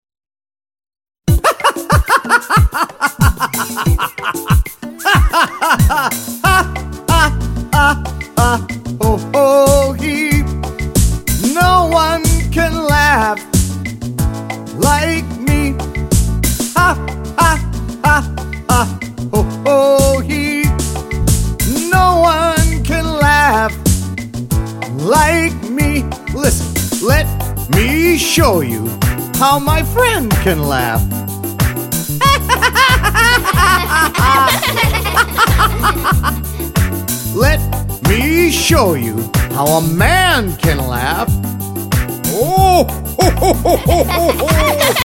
-23 simple, catchy songs
-Kids and adults singing together and taking verbal turns